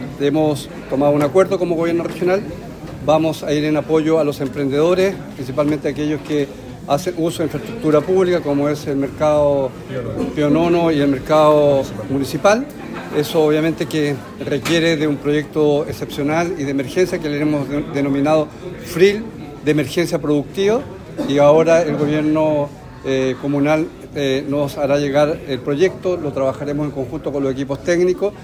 Otro de los temas importantes para los habitantes de la comuna es el apoyo a los emprendedores. Sobre eso, el gobernador Alejandro Santana anunció un Fondo Regional de Inversión Local para levantar las estructuras públicas afectadas, como es el Mercado Municipal y Pío Nono.